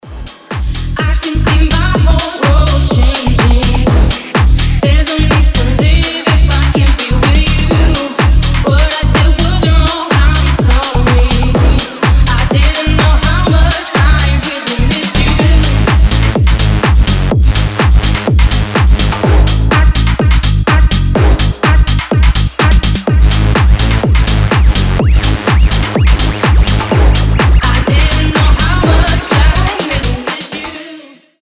Electronic
EDM
Tech House
ремиксы